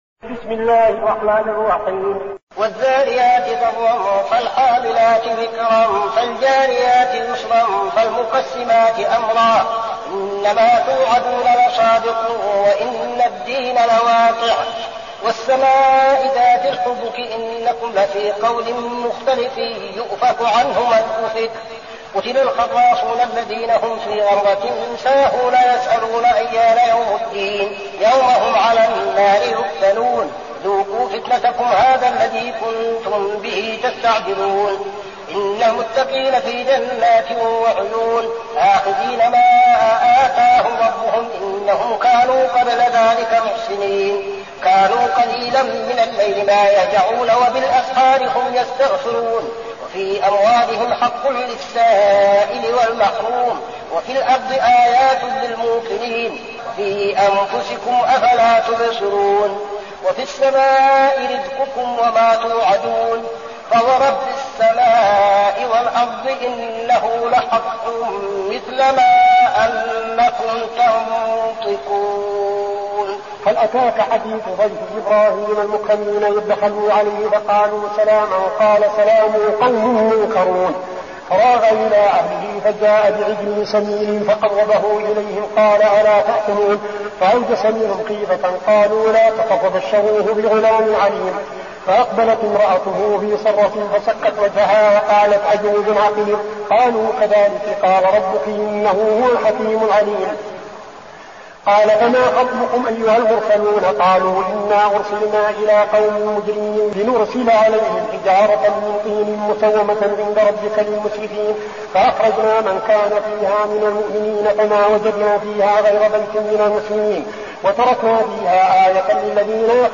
المكان: المسجد النبوي الشيخ: فضيلة الشيخ عبدالعزيز بن صالح فضيلة الشيخ عبدالعزيز بن صالح الذاريات